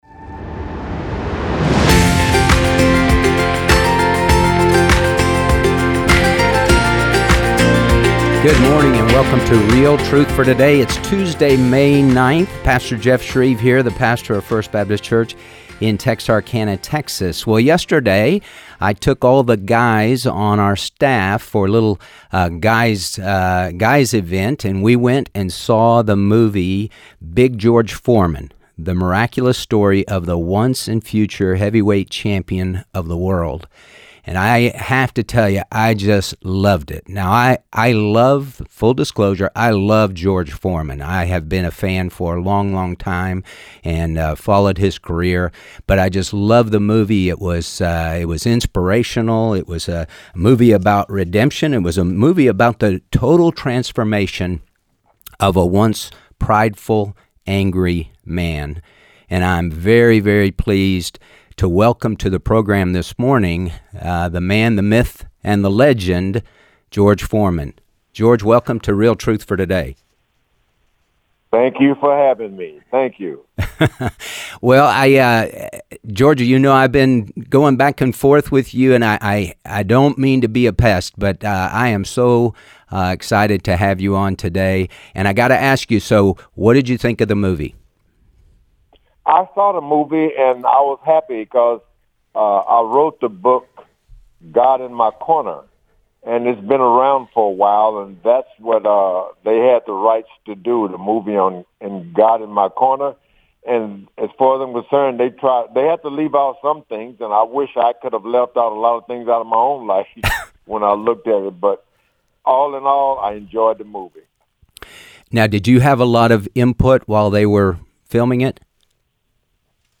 A Conversation with George Foreman